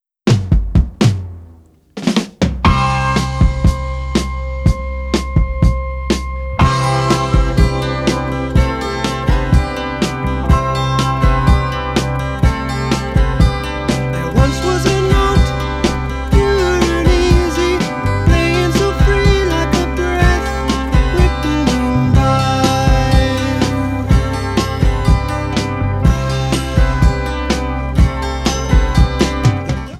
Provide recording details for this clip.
The 2006 CDs sound a bit louder.